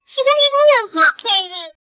Free AI Sound Effect Generator
goofy-gomjgt5i.wav